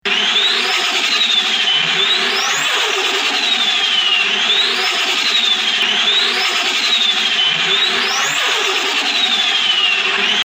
Звуки турбины двигателя
• Качество: высокое
На этой странице вы можете слушать и скачивать онлайн коллекцию реалистичных аудиозаписей: от свиста турбонаддува спортивного автомобиля до оглушительного рева реактивного двигателя.